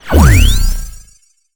sci-fi_shield_device_power_up_01.wav